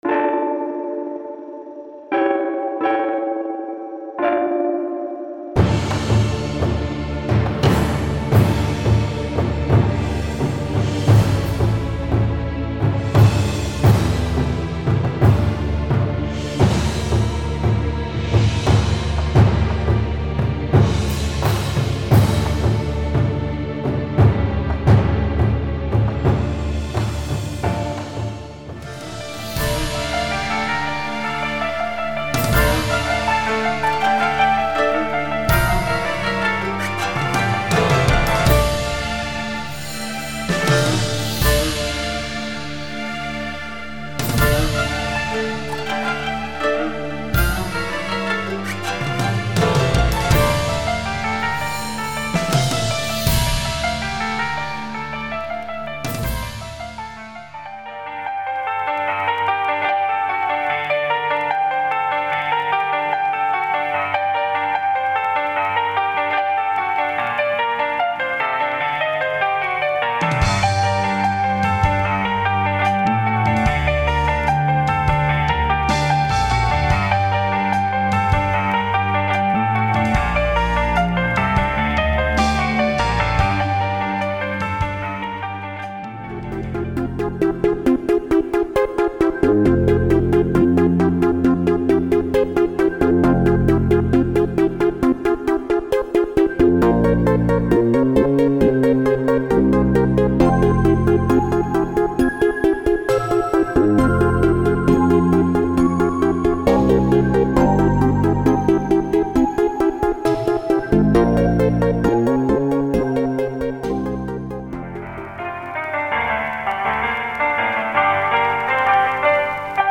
Hip HopTrap
其中包括每种乐器的完整词干，因此您可以按照自己的方式混合所有内容。
如果他们还没有赢得您，请务必查看展示30秒的音频演示。
•10条完整的器乐曲目